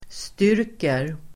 Uttal: [st'yr:ker]